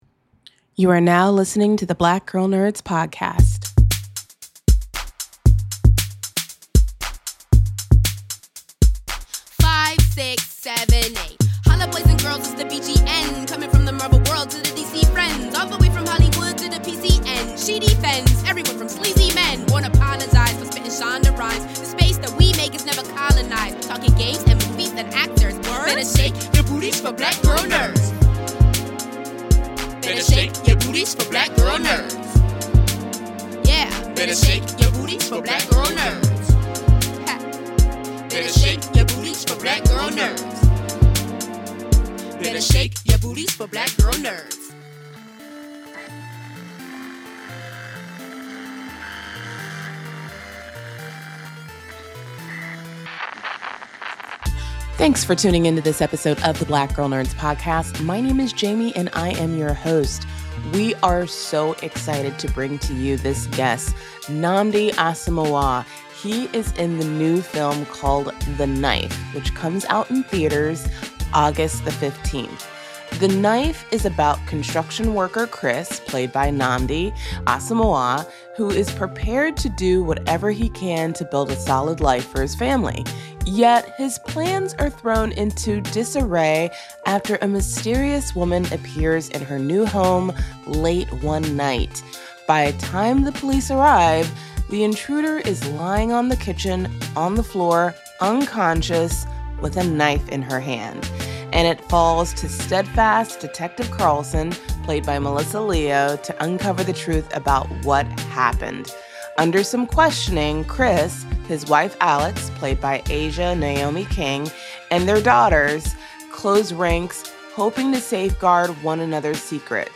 In this week's episode of the Black Girl Nerds podcast, we chat with actor, writer and director Nnamdi Asomugha about his new film The Knife.